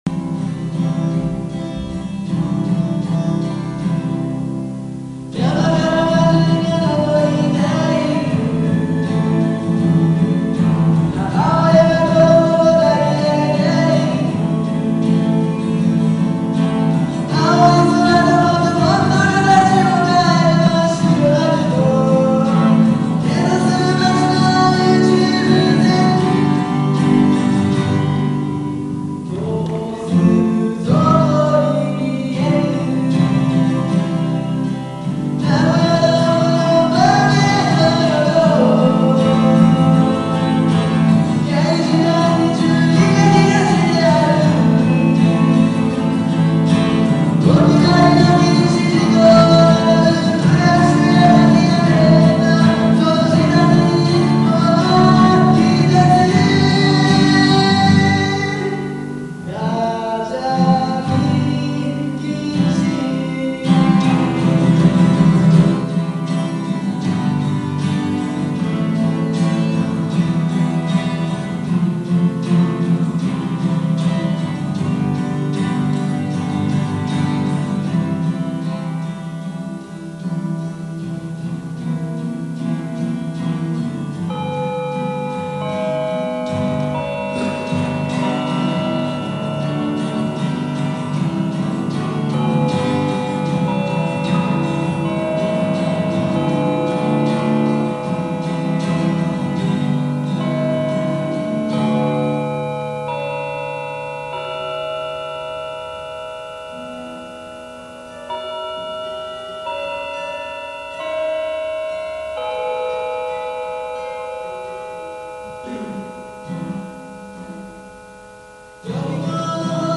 （録音場所：某体育館）